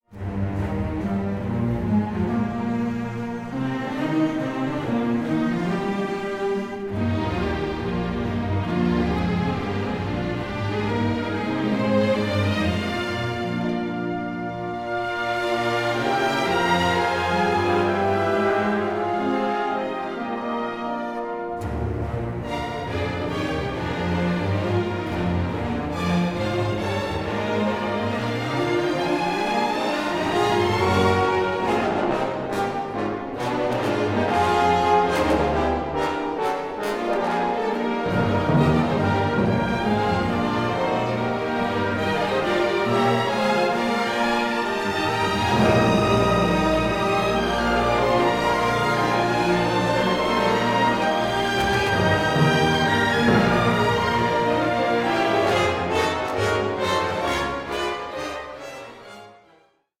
tone poem
it’s a bold, optimistic and passionate work